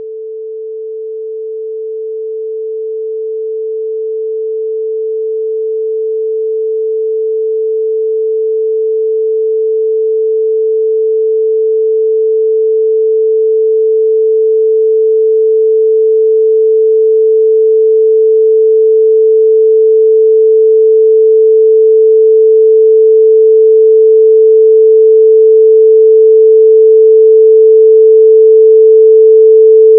chirp.wav